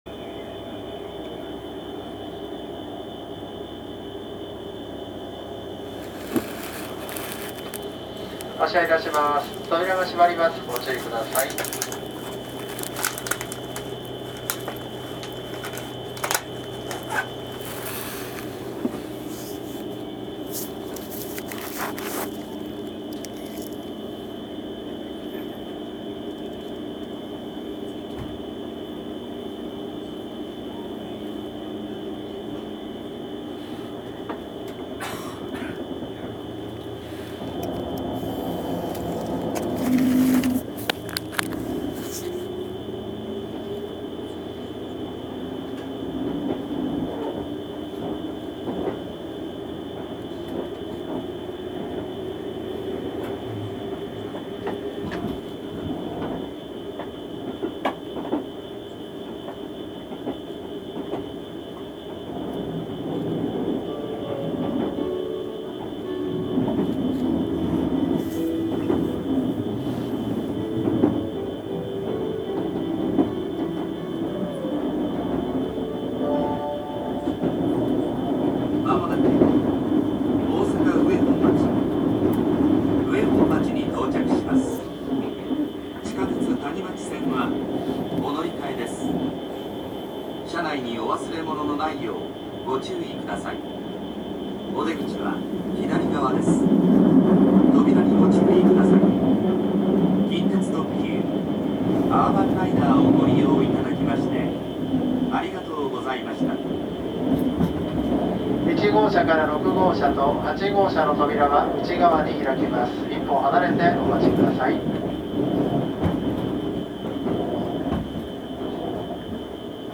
走行音
録音区間：鶴橋～大阪上本町駅(アーバンライナー)(